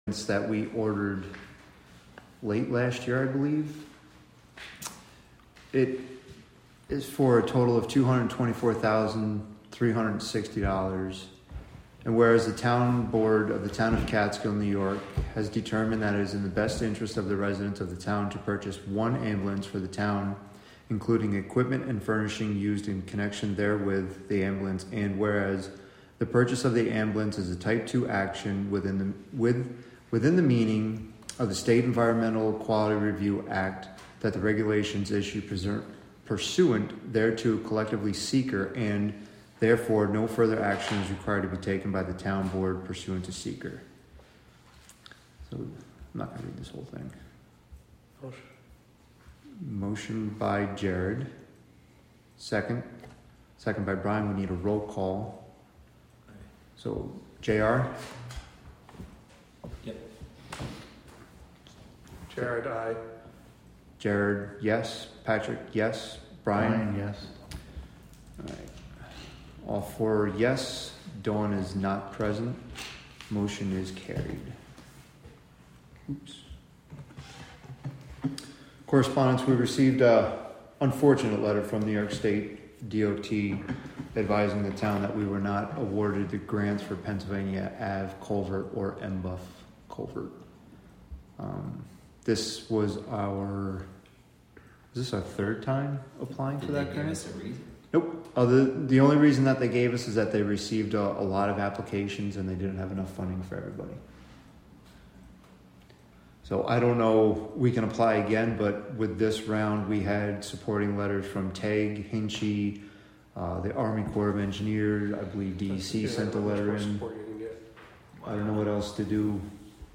Live from the Town of Catskill: August 6, 2024 Catskill Town Board Meeting (Audio)